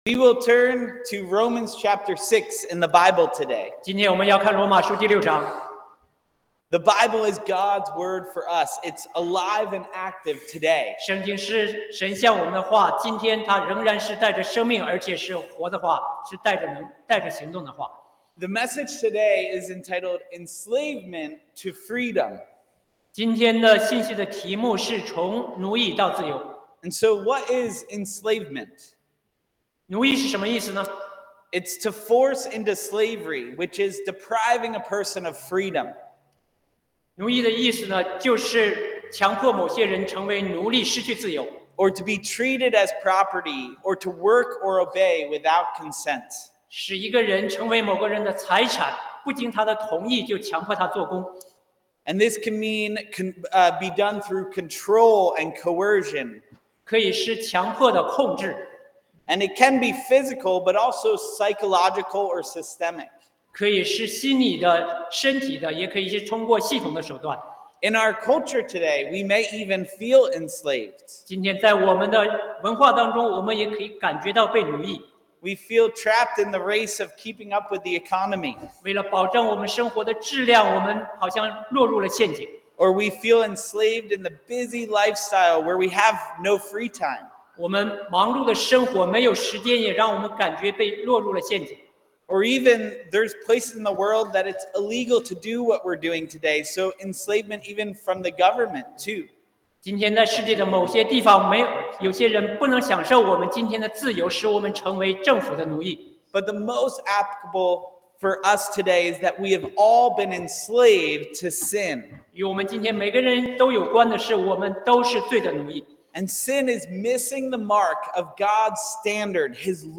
福音聚会